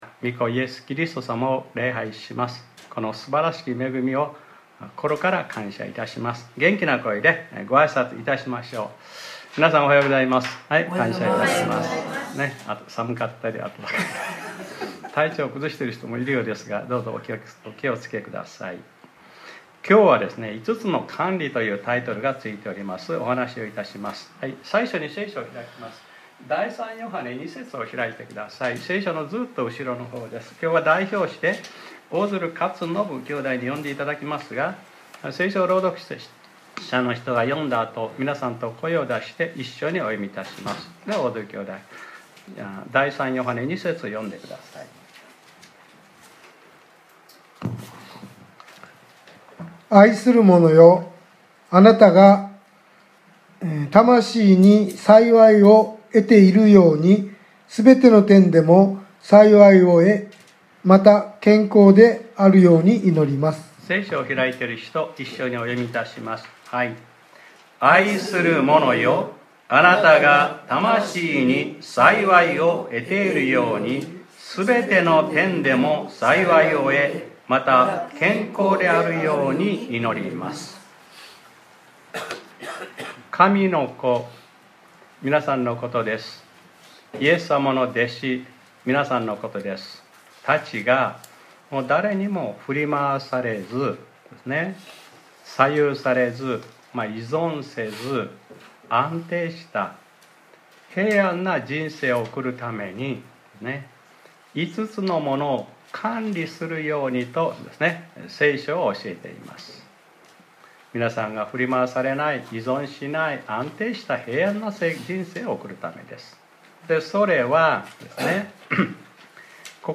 2025年03月02日（日）礼拝説教『 ５つの管理 』